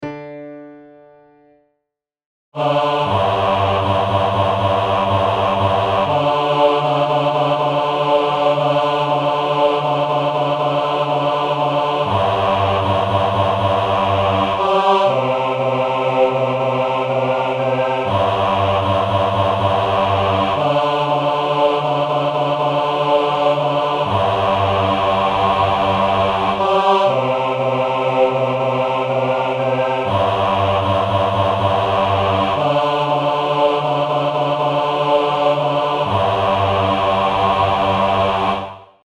Bajazzo, Deutsches Volkslied
Tempo- und Lautstärken-Variationen sowie andere Ausdrucksvorgaben wurden nur wenig berücksichtigt.
BajazzoBass.mp3